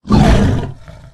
boar_attack_1.ogg